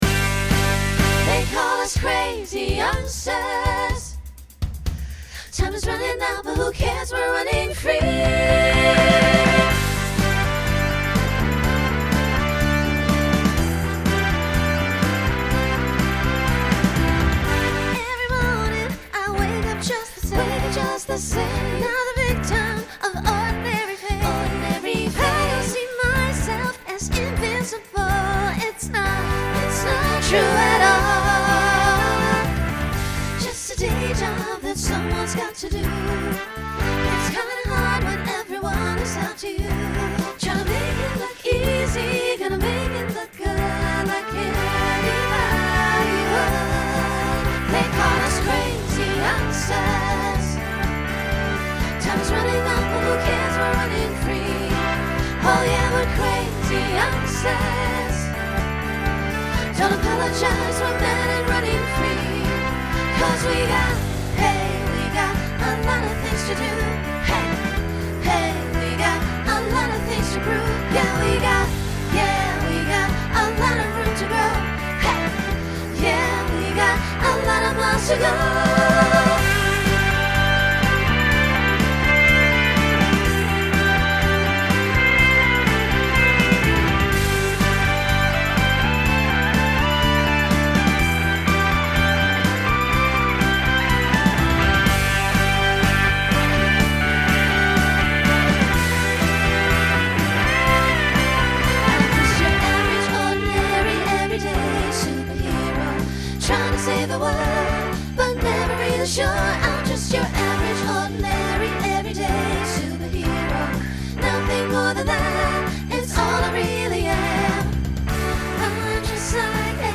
Pop/Dance , Rock Instrumental combo
Voicing SSA